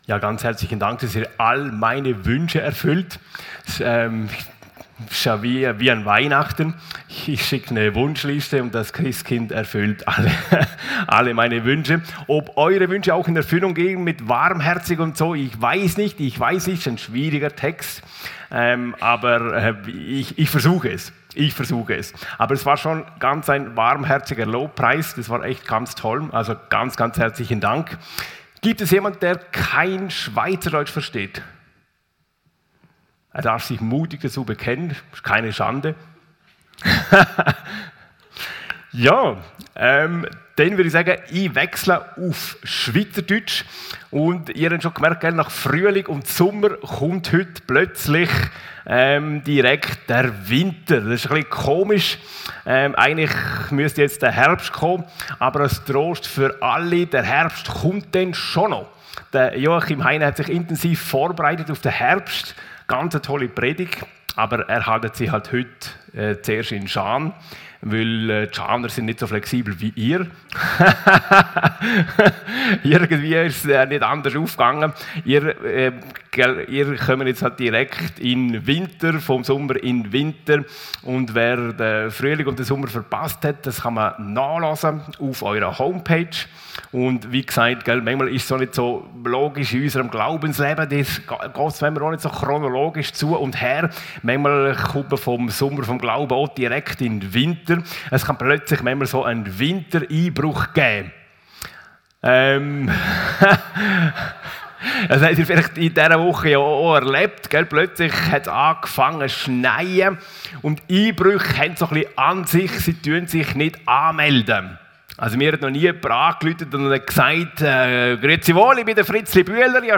Predigt 24. November 2024